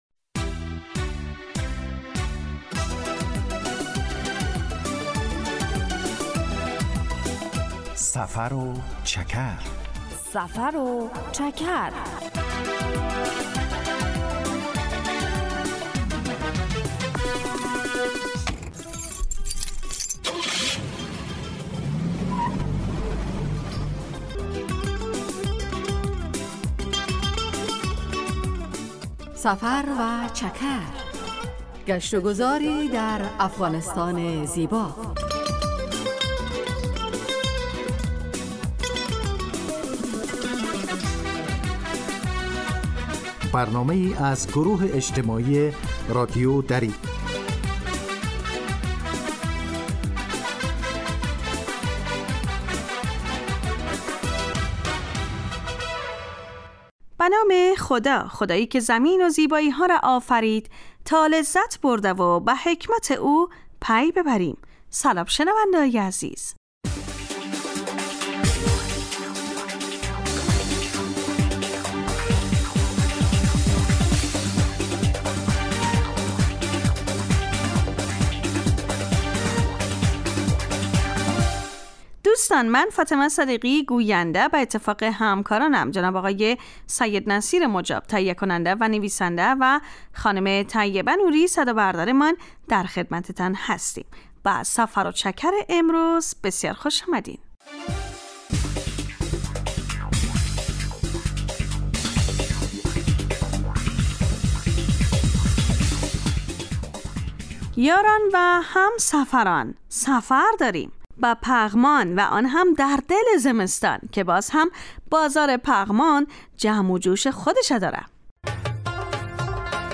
سفر و چکر برنامه ای 15 دقیقه از نشرات رادیو دری است که به معرفی ولایات و مناطق مختلف افغانستان می پردازد. در این برنامه مخاطبان با جغرافیای شهری و فرهنگ و آداب و سنن افغانی آشنا می شوند. در سفر و چکر ؛ علاوه بر معلومات مفید، گزارش و گفتگو های جالب و آهنگ های متناسب هم تقدیم می شود.